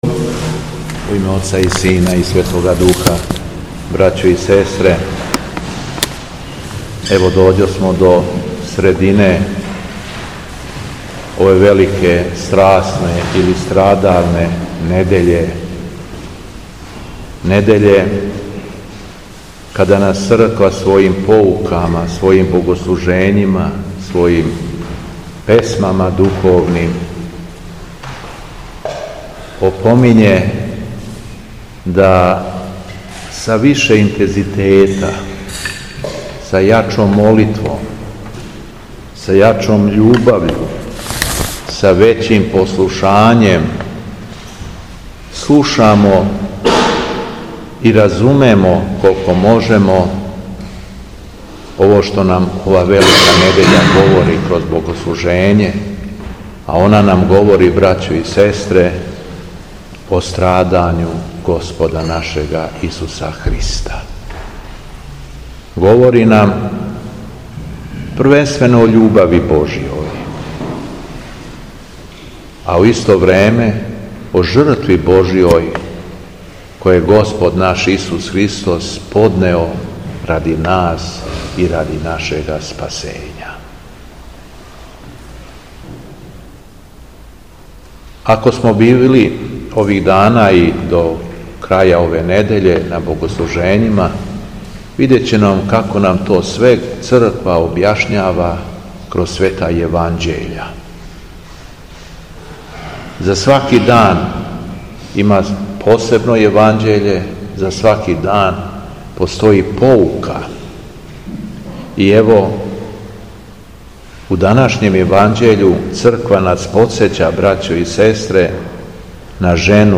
Беседа Његовог Високопреосвештенства Митрополита шумадијског г. Јована
После прочитаног јеванђелског зачала преосвећени владика се обратио окупљеном верном народу богоугодном беседом.